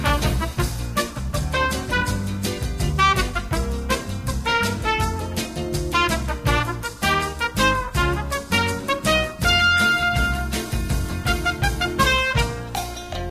Мелодия
звучит во время титров